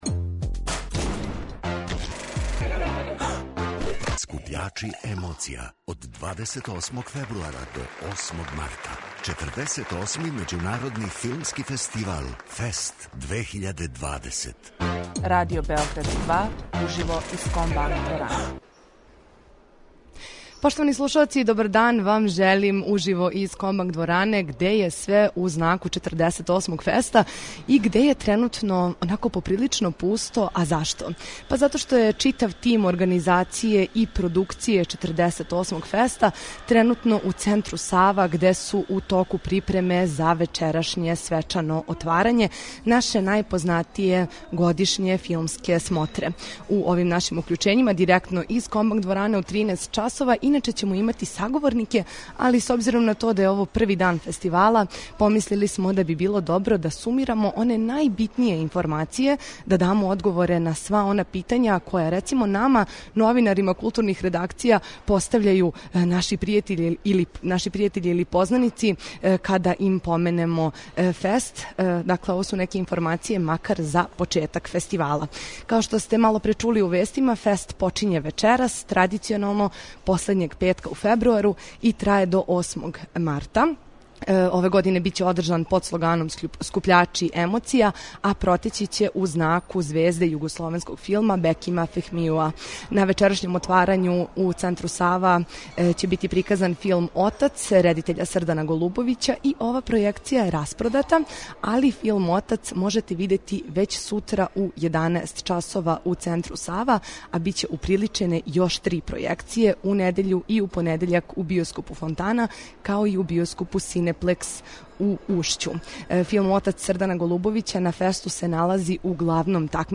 Овог петка представићемо вам један од бучнијих престоничких рок састава. Стиче се утисак да је последњих година поново стасала нова генерација бендова који редефинишу давно одсвиране панк и новоталасне рифове.